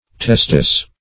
Testis \Tes"tis\, n.; pl. Testes. [L.] (Anat.)